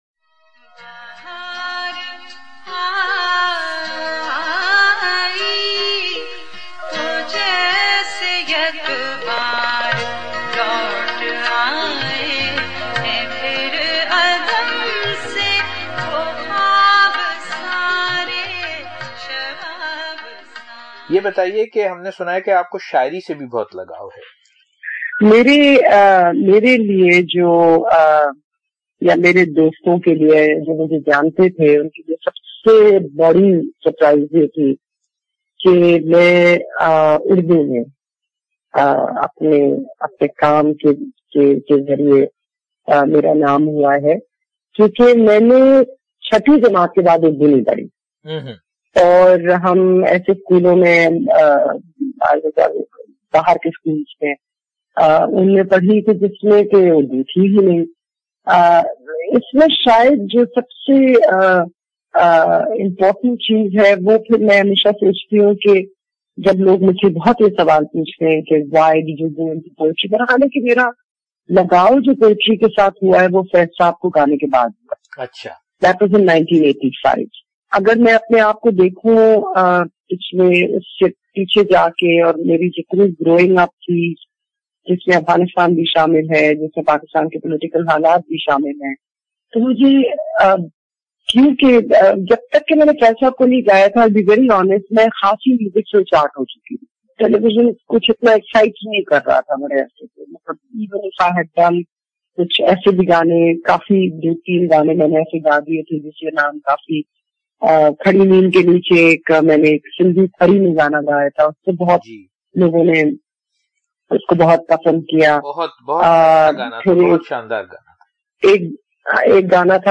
Part 2 A journey of self exploration. Conversation with famous Pakistani singer Tina Sani